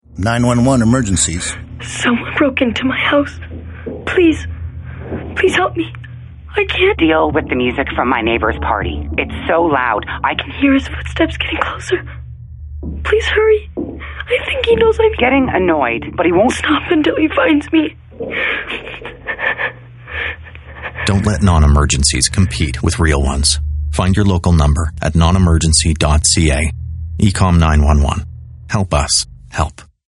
SilverPublic Service - Radio Single
Overpower Radio Spot